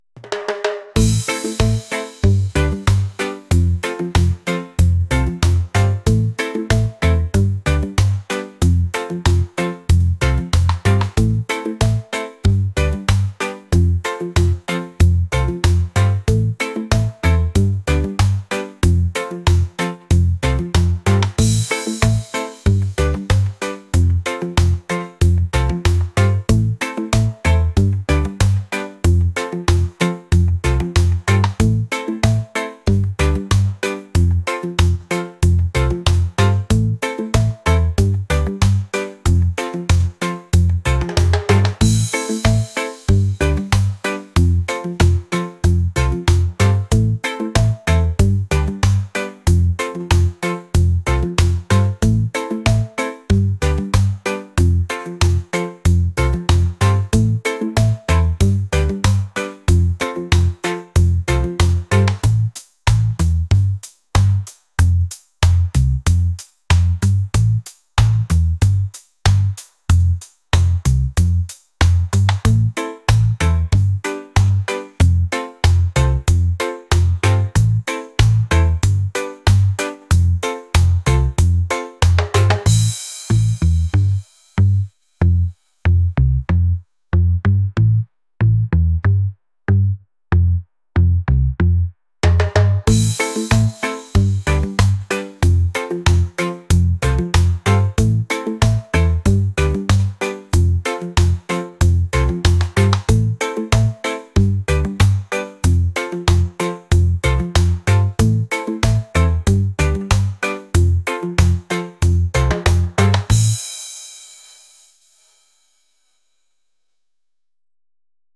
reggae | lounge | lofi & chill beats